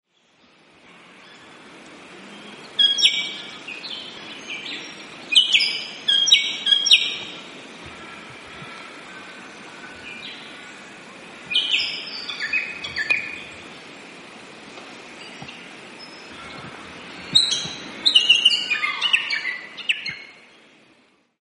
Crescent Honeyeater - Phylidonyris Pyrrhoptera
Feed in canopy, sometimes hard to see though call is loud and distinctive.
Voice: high-pitched, emphatic 'egypt'.
Call 1: 'egypt' calls; Sulphur-crested Cockatoo and Little Raven also audible
Crescent_Hon_egypt.mp3